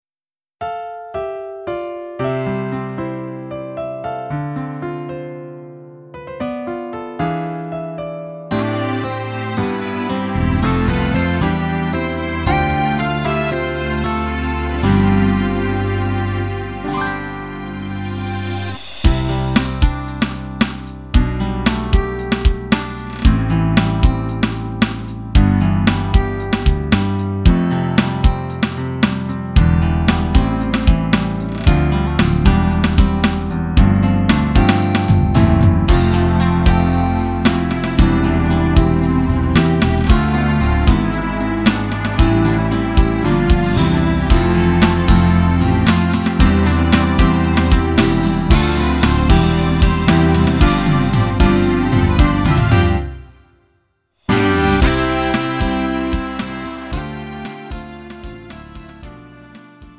음정 -1키 4:05
장르 구분 Lite MR